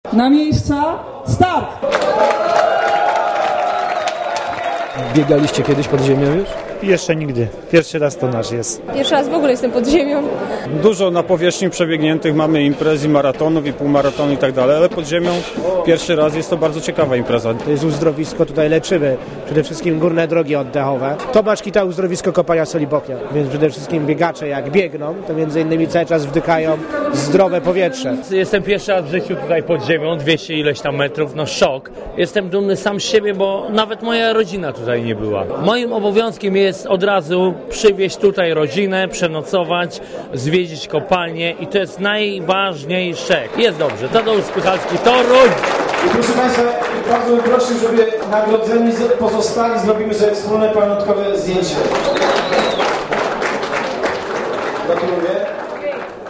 Mówią uczestnicy biegu Najdłuższy dystans pokonali biegacze z klubu Noteć Browar Czarnków z Wielkopolski.